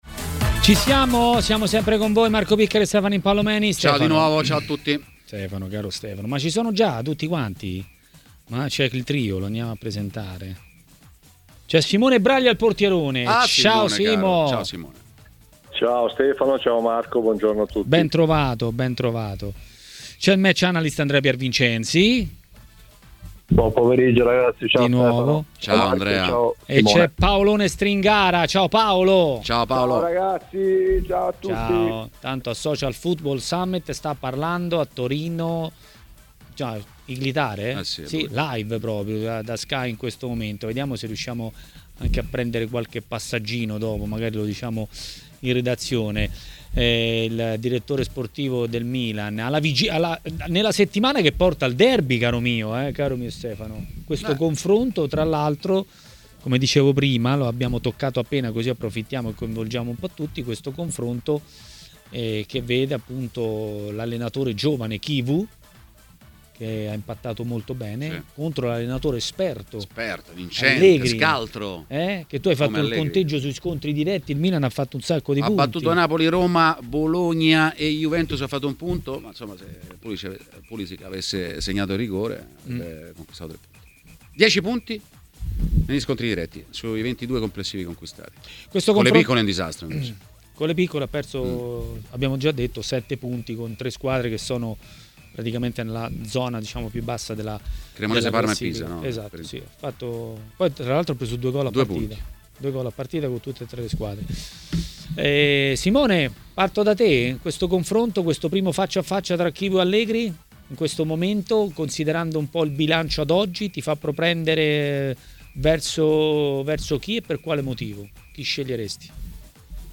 A Maracanà, nel pomeriggio di TMW Radio, è intervenuto l'ex portiere Simone Braglia.